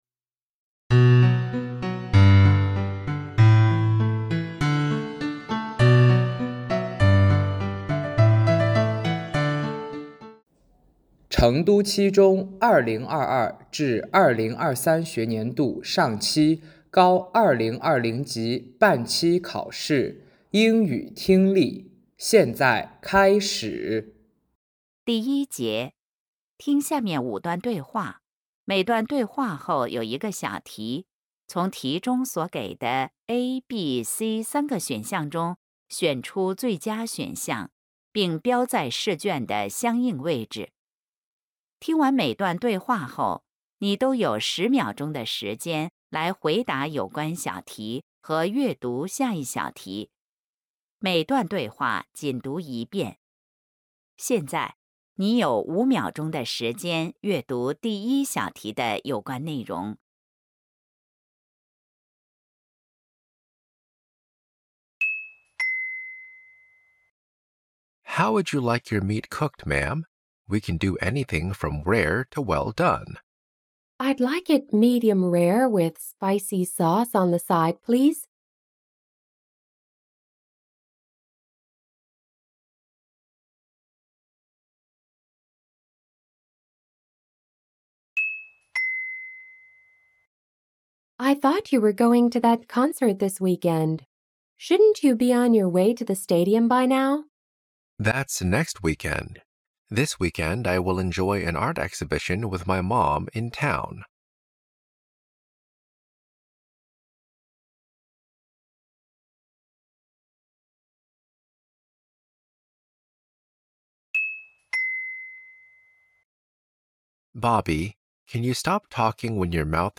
23届高三英语上期半期考试试卷听力.mp3